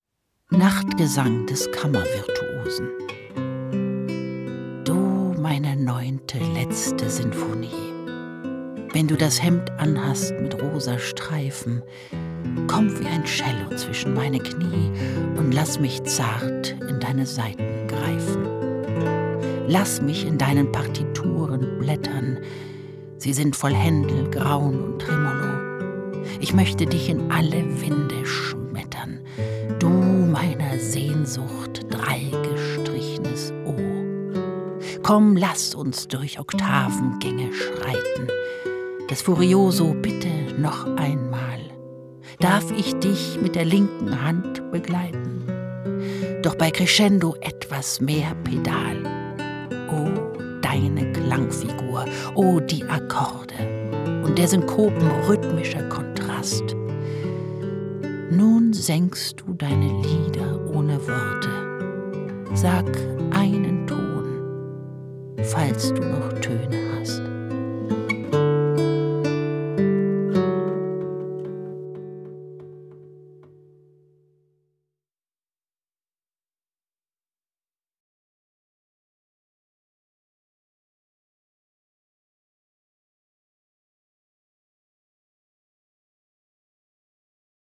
Claudia Michelsen liest Kästner
spielt Gitarre